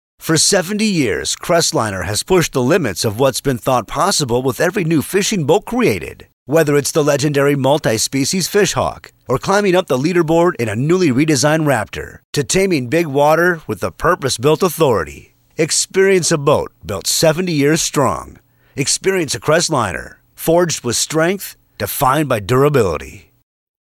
Male
Yng Adult (18-29), Adult (30-50)
My voice is best described as conversational, compelling and friendly. Can be comedic and sarcastic on the drop of a dime, yet overly- friendly and smooth at the same time.
Television Spots
Words that describe my voice are Strong, Fast, Smooth.